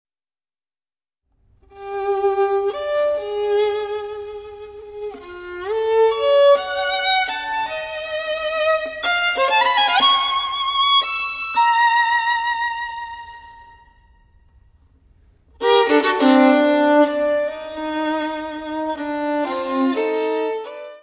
: stereo; 12 cm + folheto
Área:  Música Clássica
violin solo